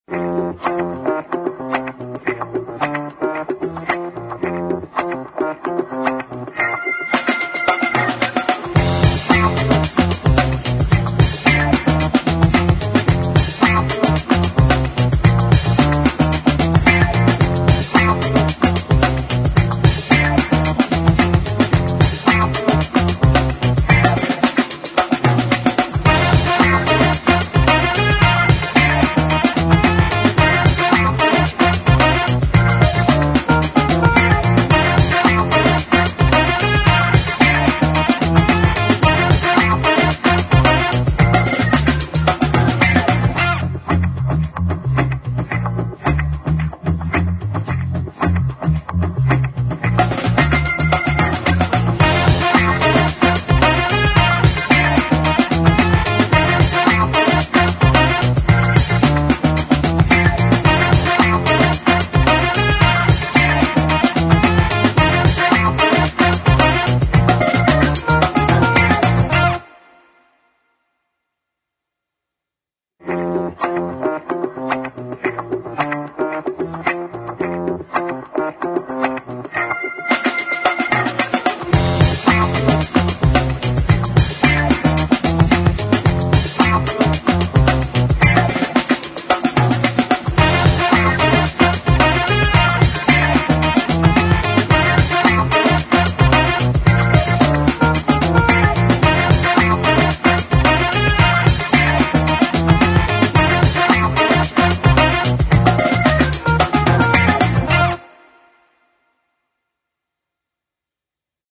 放克爵士